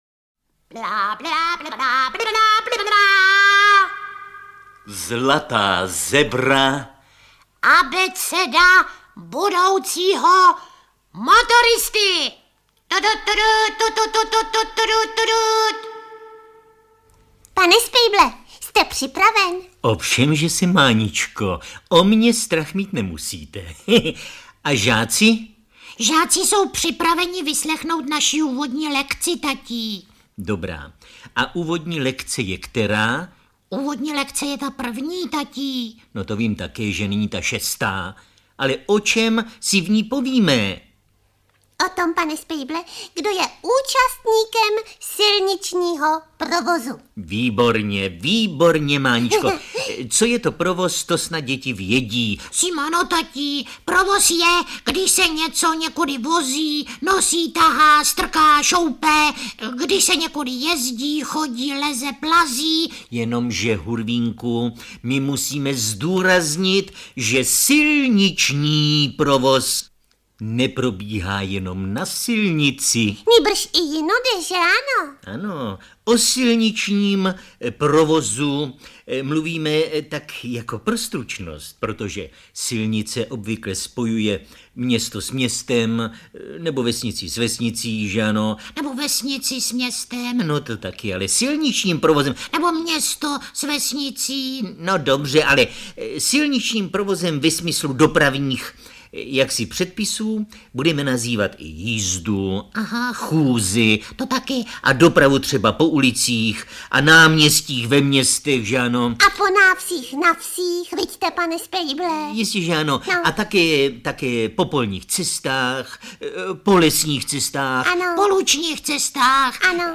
Ukázka z knihy
• InterpretMiloš Kirschner, Helena Štáchová